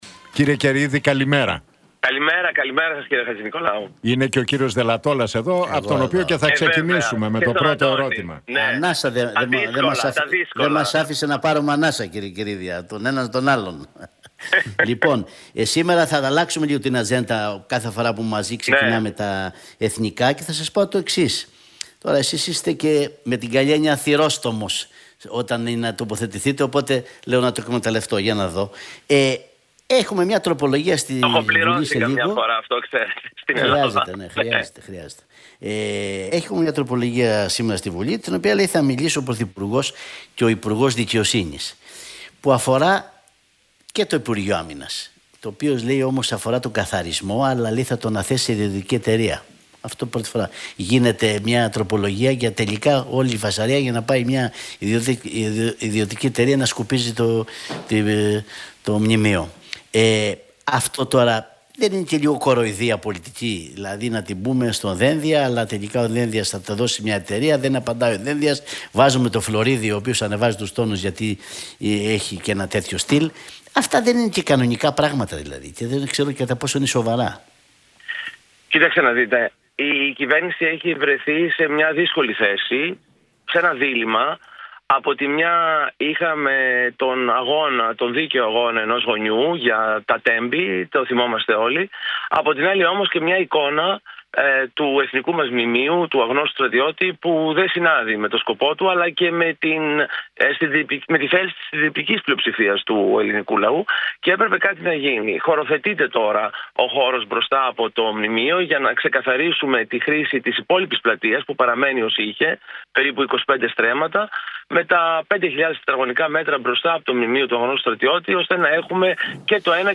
από τη συχνότητα του Realfm 97,8